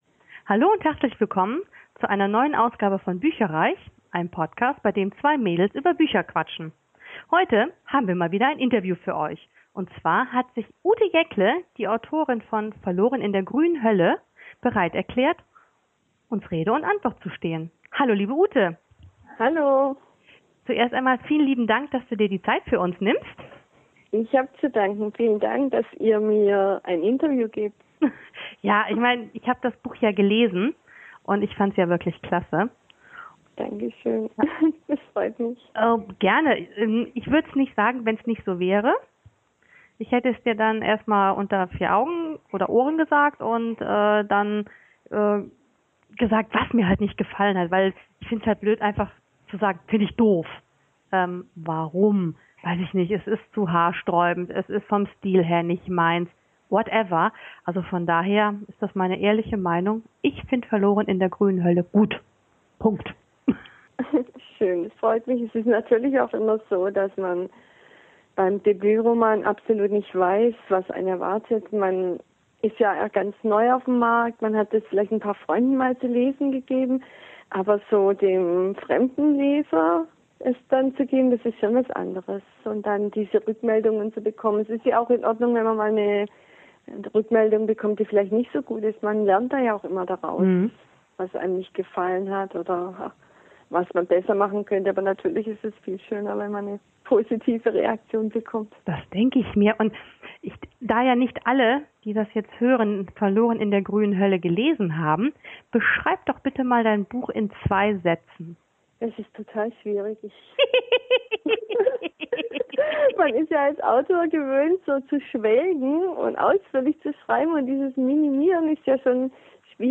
Beschreibung vor 11 Jahren Willkommen bei bücherreich, unserem Bücher-Podcast! Wir freuen uns euch wieder ein Interview präsentieren zu können.
Entschuldigt bitte, dass die Tonqualität leider nicht auf unserem üblichen Niveau ist, da das Interview via Skype geführt und aufgenommen wurde.